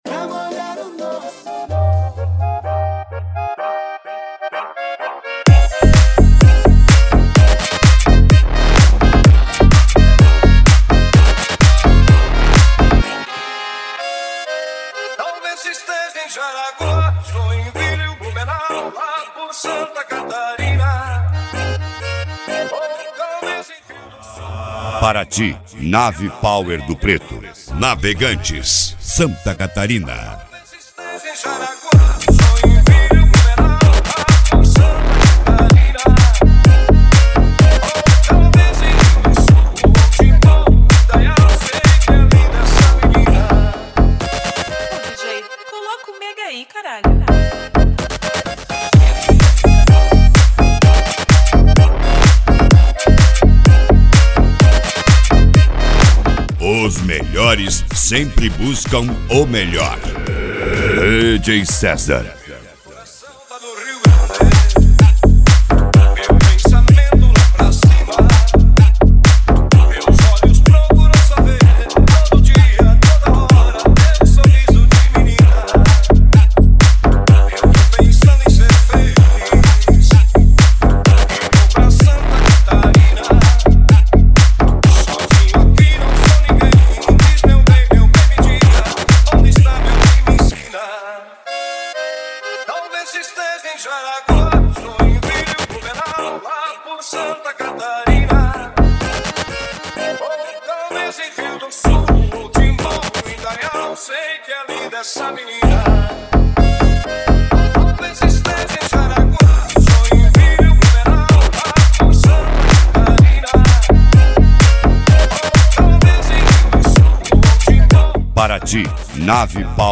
Mega Funk
SERTANEJO